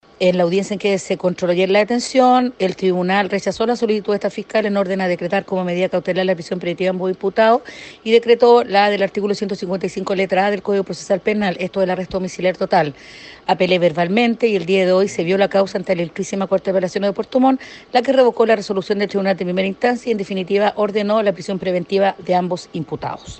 Seguidamente, la persecutora expresó que después de la presentación ante la Corte de Apelaciones de Puerto Montt, esta instancia desestimó lo fallado en el tribunal de Quellón, determinando la prisión preventiva.